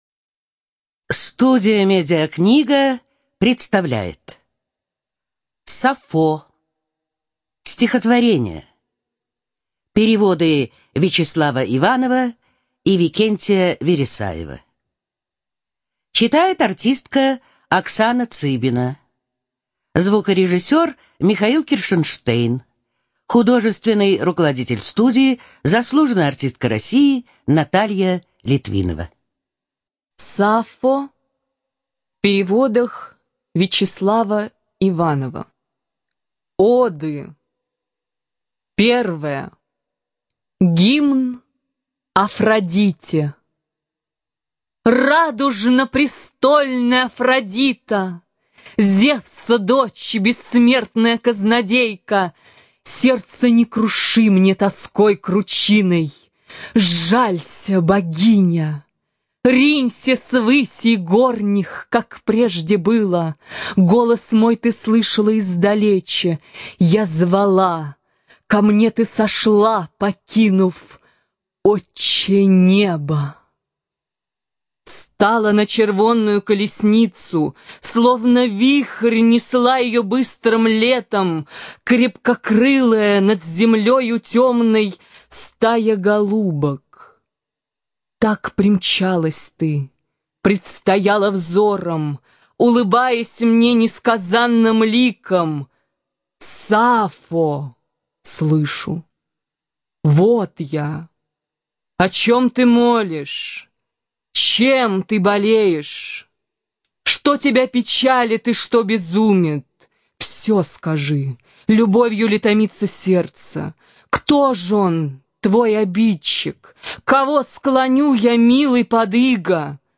ЖанрПоэзия
Студия звукозаписиМедиакнига